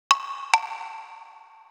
Techno / Drum / PERCUSSN031_TEKNO_140_X_SC2.wav
1 channel